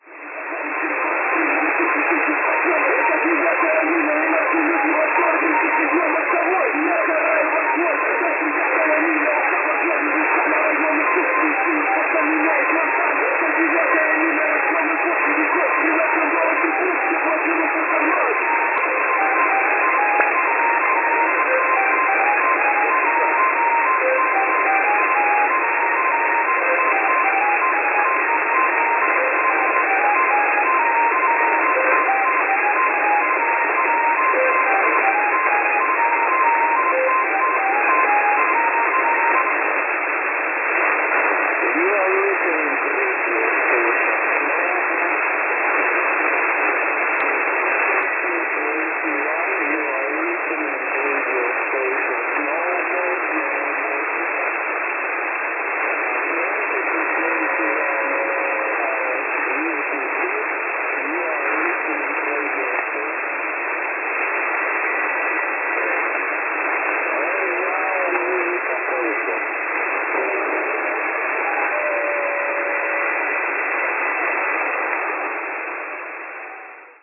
Russian rap-music and announcements in English on 6630 kHz!
mp3-clip at 20.12 utc. Deep fading.